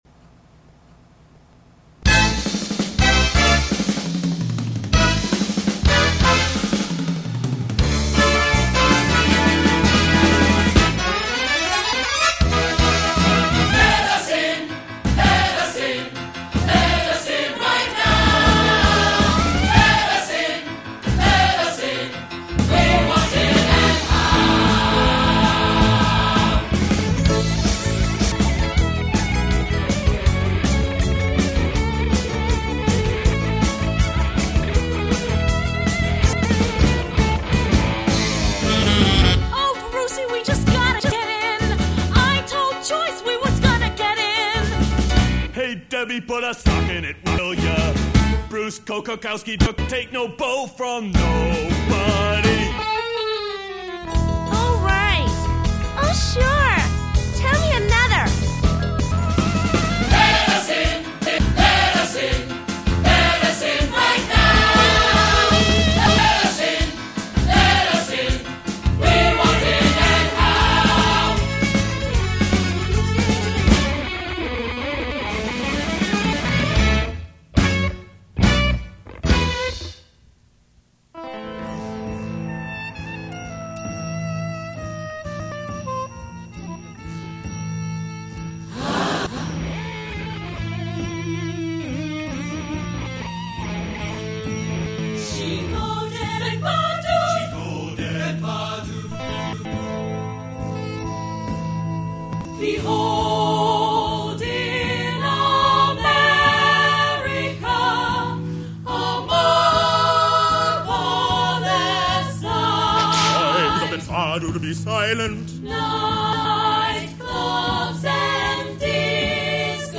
Instrumentals, vocal ensembles, and solos